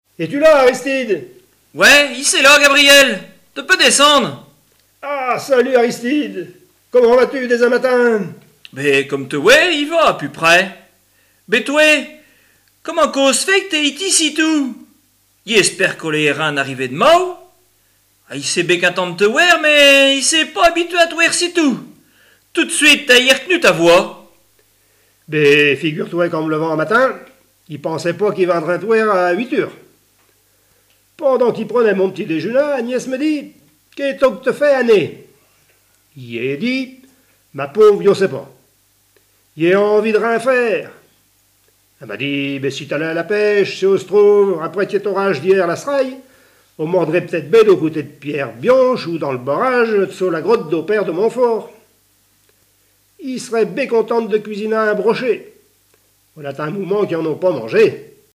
Patois local
Genre sketch